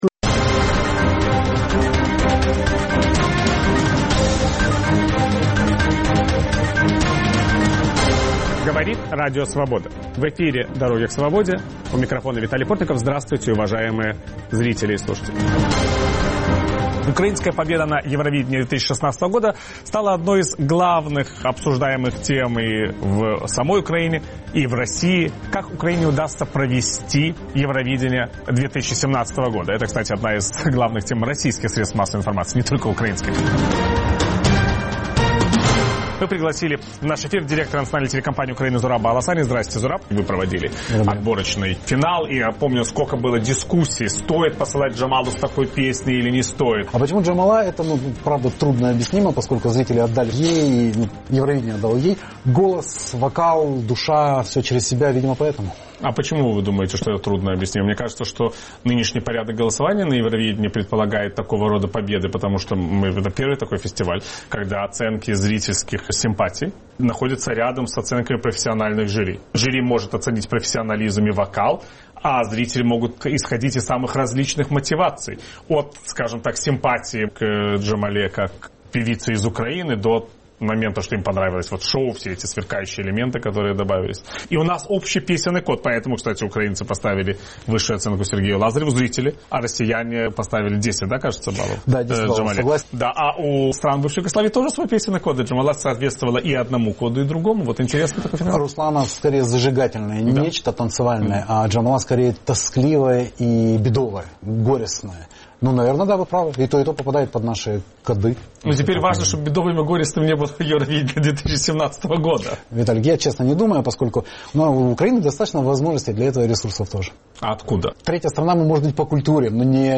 Виталий Портников беседует с директором Национальной телекомпании Украины Зурабом Аласанией